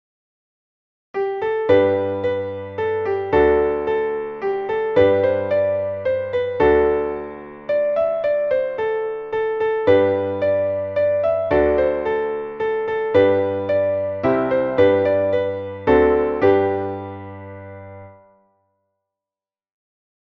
Traditionelles Lied